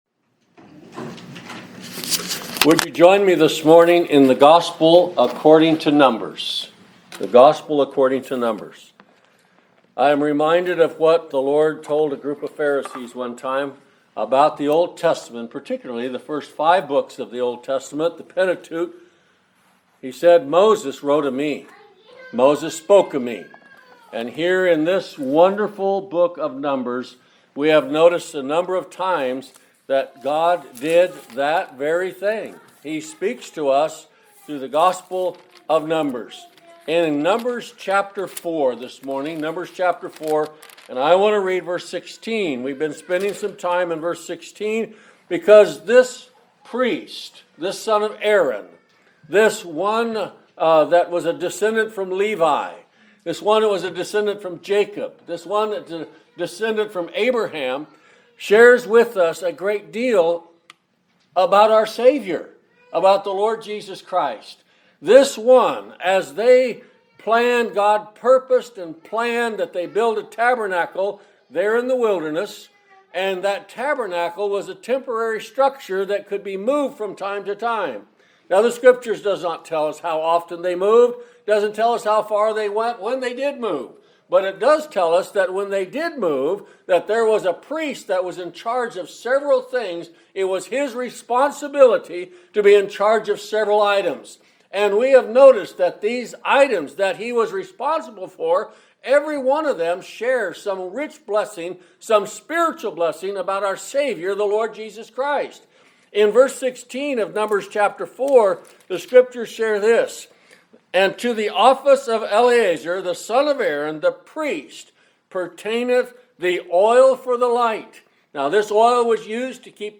He Has the Oversight | SermonAudio Broadcaster is Live View the Live Stream Share this sermon Disabled by adblocker Copy URL Copied!